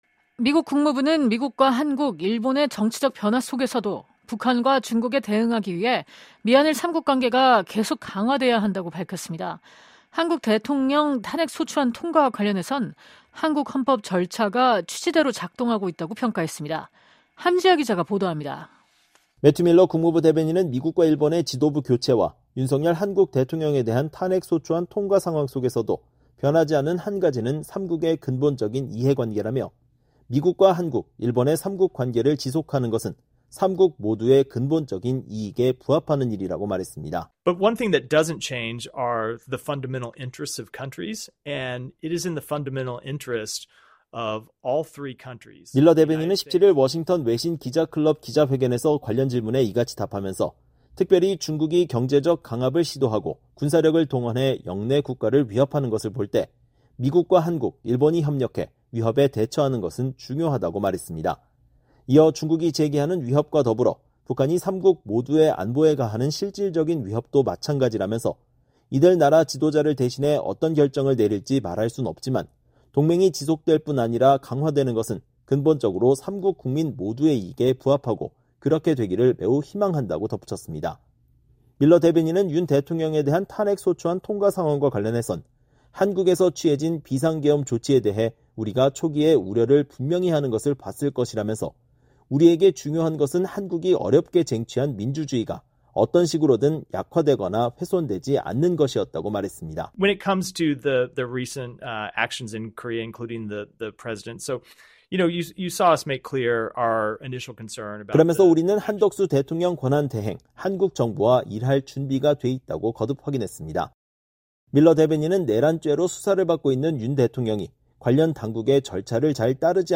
매튜 밀러 국무부 대변인이 17일 브리핑을 하고 있다.